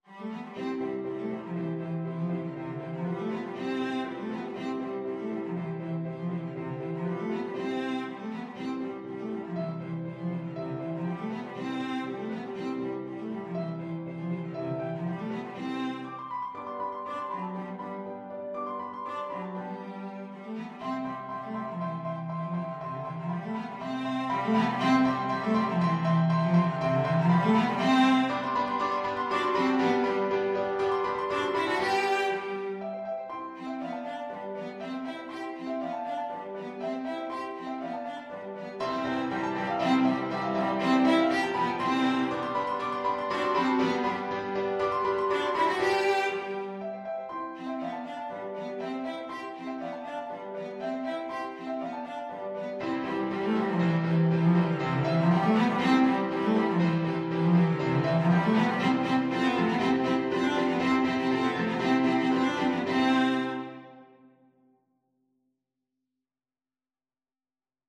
Bacchus lebe! from The Abduction from the Seraglio Cello version
Cello
2/4 (View more 2/4 Music)
C major (Sounding Pitch) (View more C major Music for Cello )
Classical (View more Classical Cello Music)